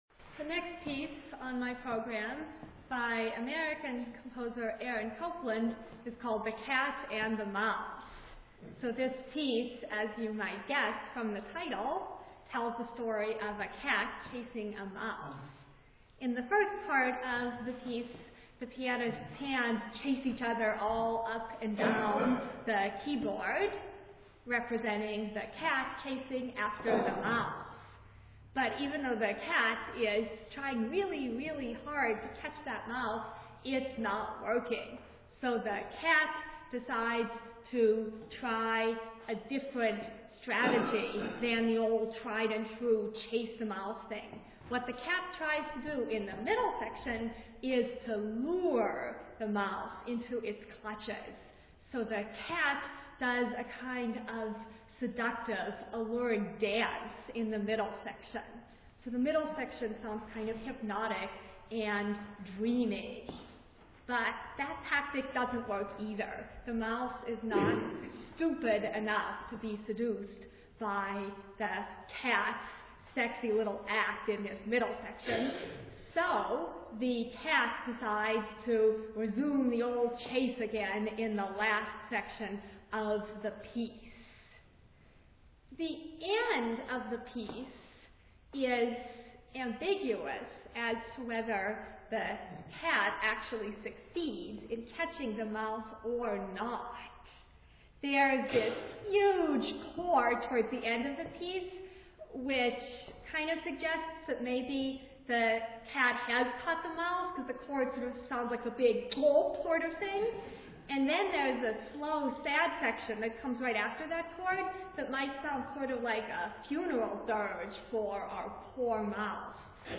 Concert Excerpt